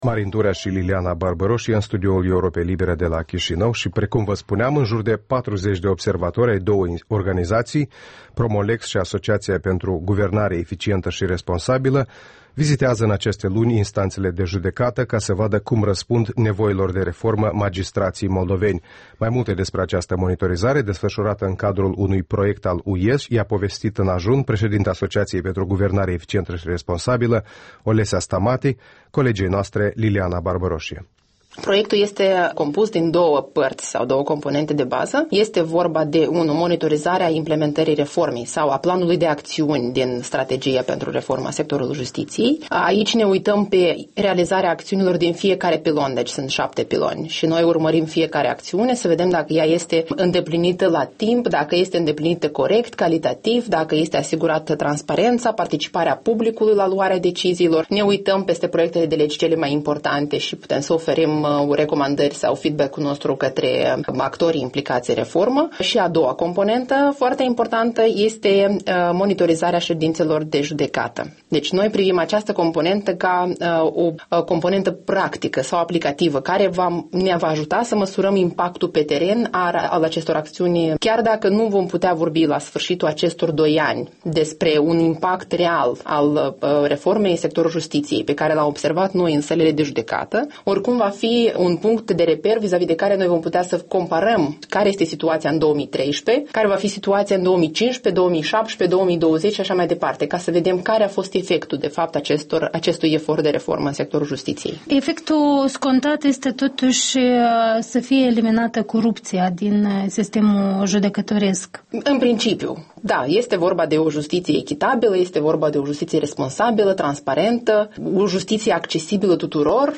Interviul dimineții: cu Olesea Stamate (AGER)